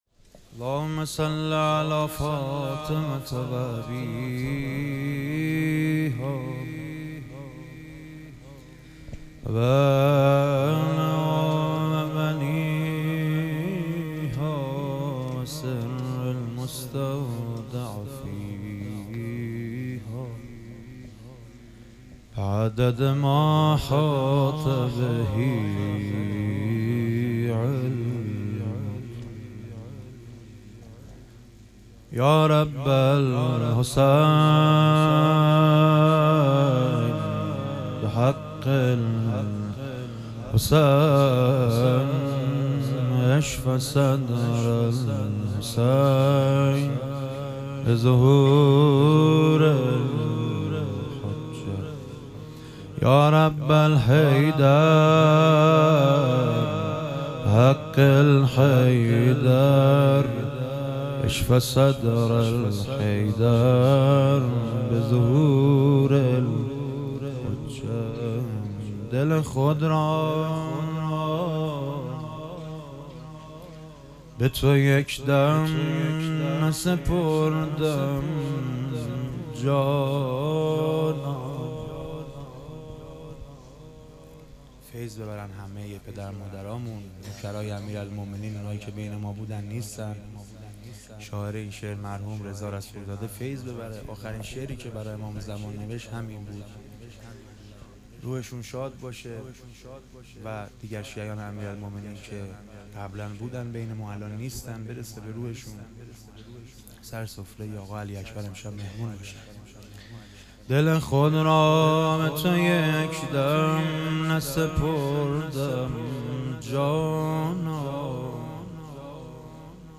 ظهور وجود مقدس حضرت علی اکبر علیه السلام - مدح و رجز